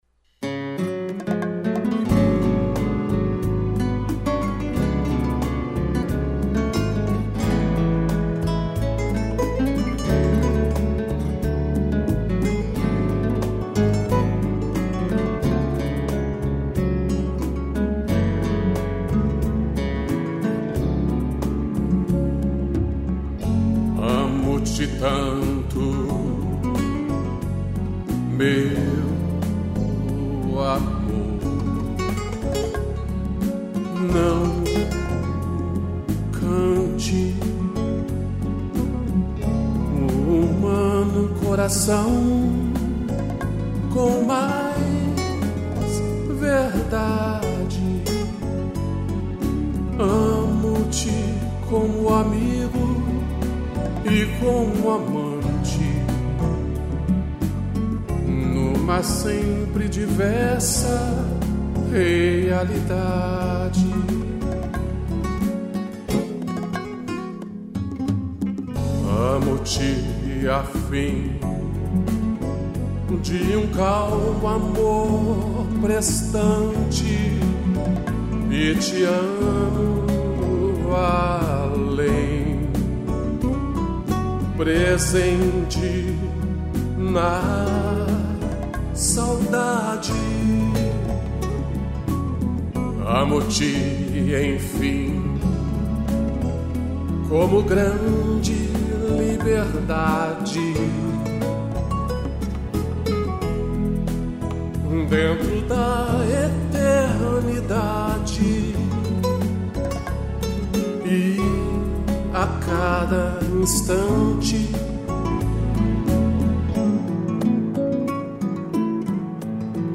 interpretação e violão